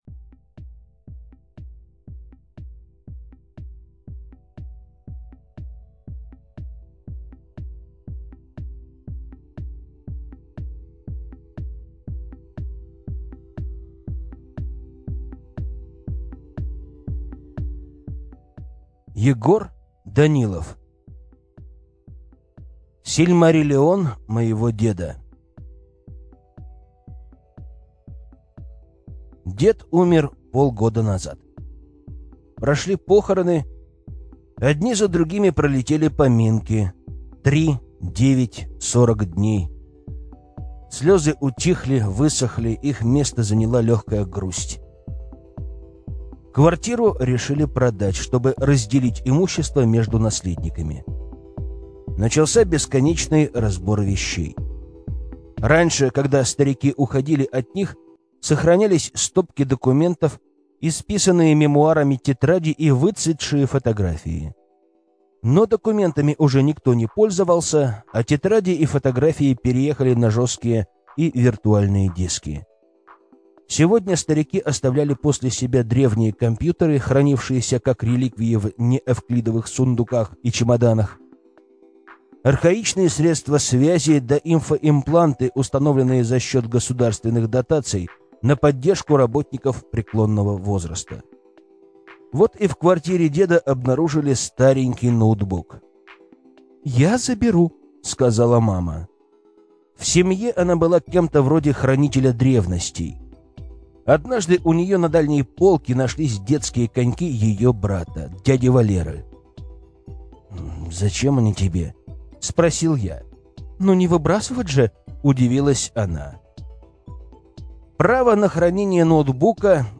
Аудиокнига Сильмариллион моего деда | Библиотека аудиокниг